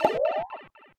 Sound effect of Pipe Floor Leave (World Map) in Super Mario Bros. Wonder